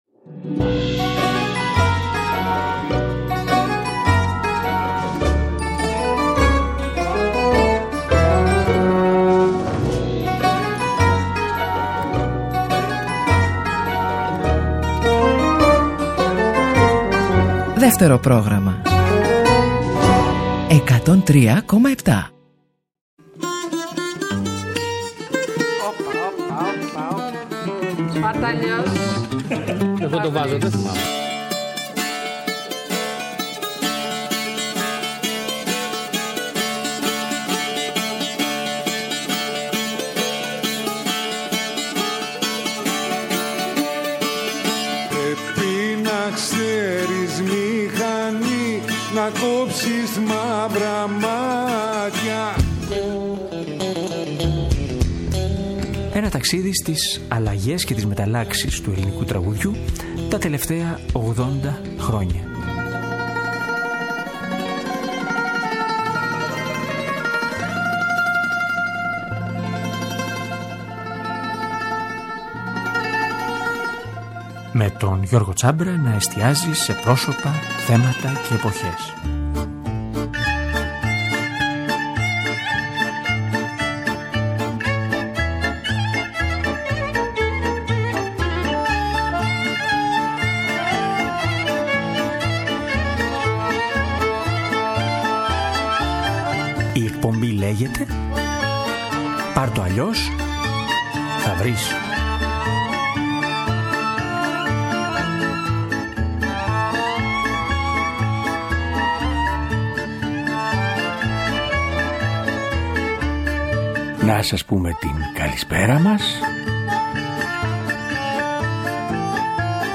Η σημερινή εκπομπή λοιπόν, είναι ένα «πάλκο» μ’ ένα απάνθισμα από σκωπτικά, σατυρικά, χιουμοριστικά, διασκεδαστικά τραγούδια που γέννησε η φαντασία Ελλήνων δημιουργών από τα προπολεμικά χρό